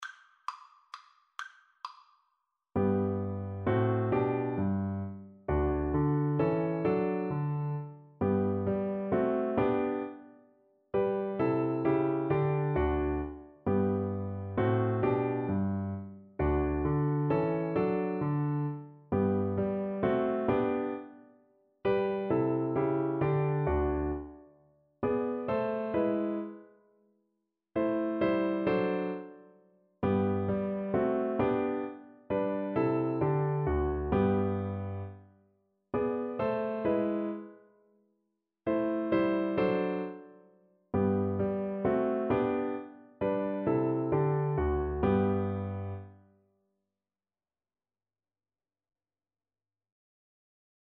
• Unlimited playalong tracks
3/4 (View more 3/4 Music)
= 132 Allegro (View more music marked Allegro)
Classical (View more Classical Violin Music)